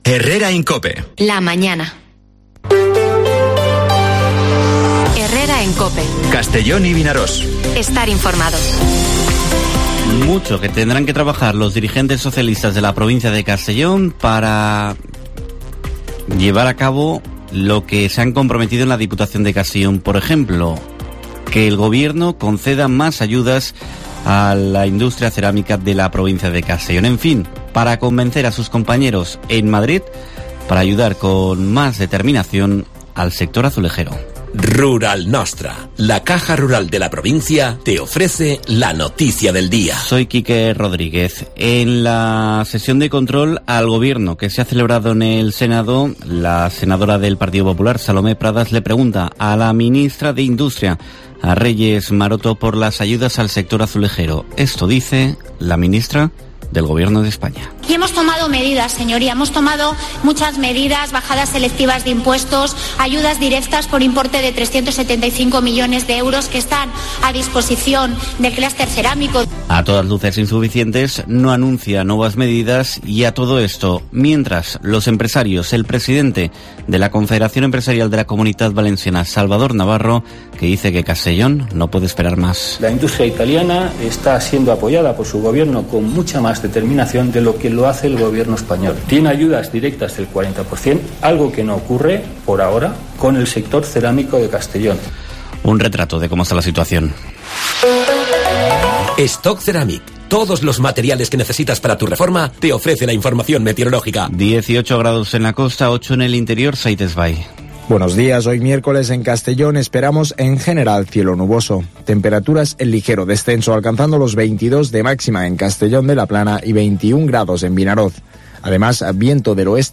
Informativo Herrera en COPE en la provincia de Castellón (16/11/2022)